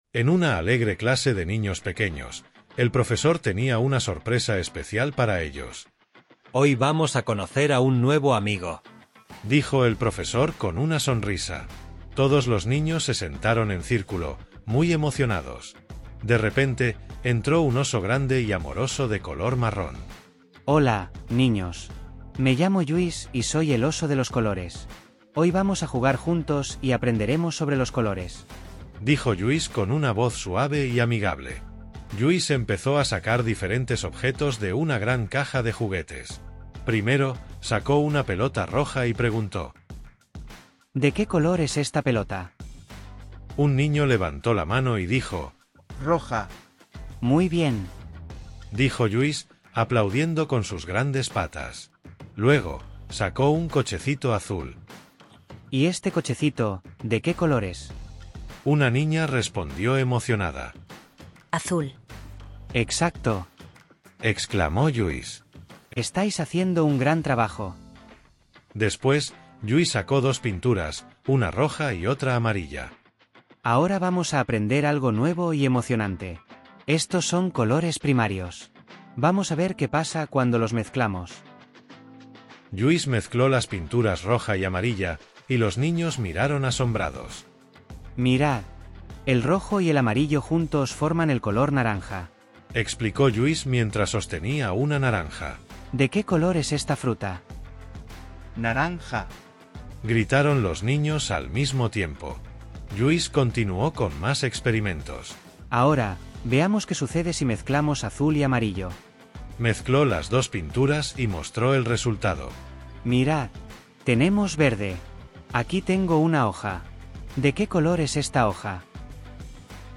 2.-Audiolibro-El-Osito-amoroso-llamado-Lluis_v3.mp3